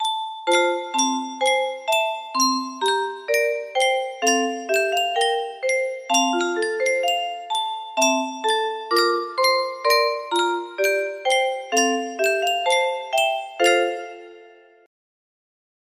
Yunsheng Music Box - O Little Town of Bethlehem Y087 music box melody
Full range 60